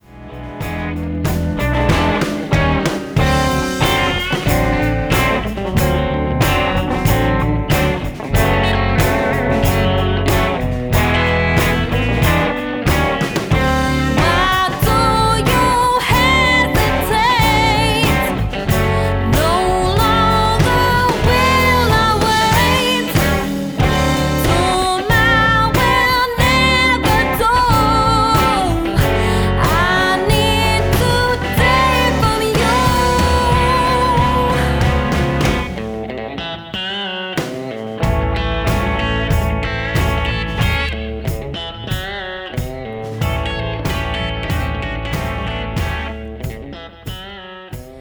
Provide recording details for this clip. An original Recorded at Third Ward Records February 2015.